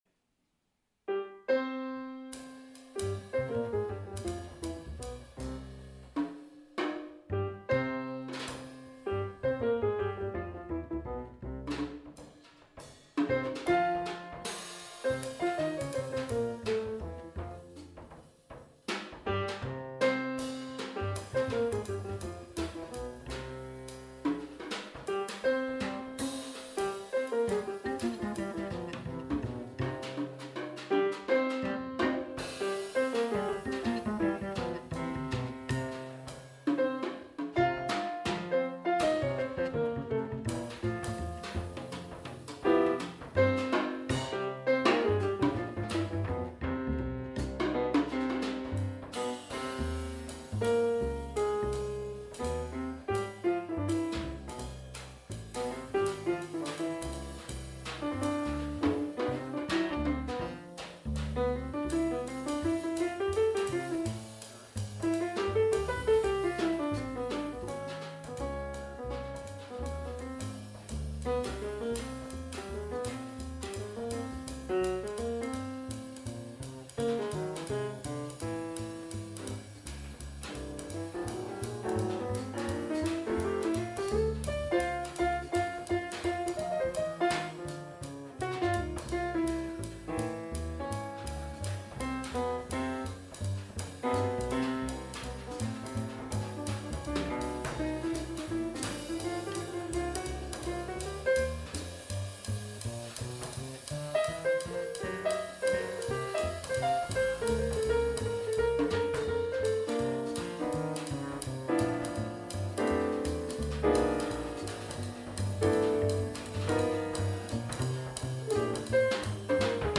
Here are some clips from recent jazz performances:
Recorded Live at the Hoson House
bass
drums